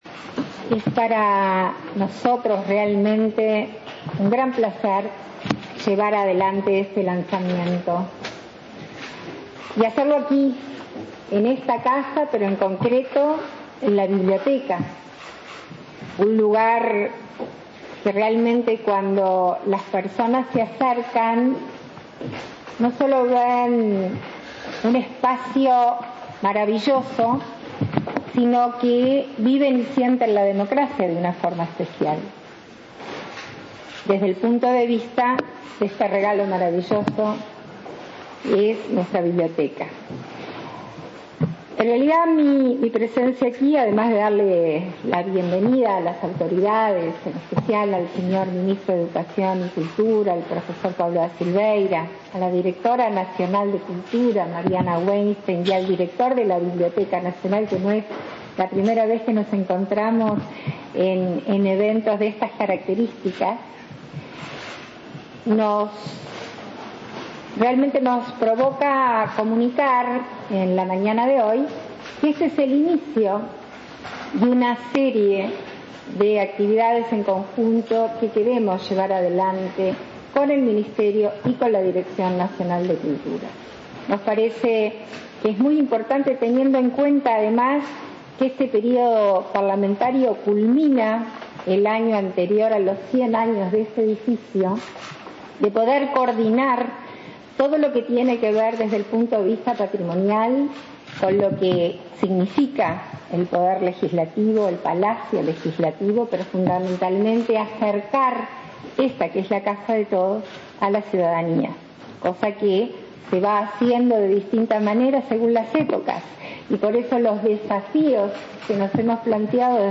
La iniciativa fue presentada este martes 30, en la biblioteca del Palacio Legislativo, en una ceremonia encabezada por la vicepresidenta, Beatriz Argimón, y el ministro de Educación y Cultura, Pablo da Silveira.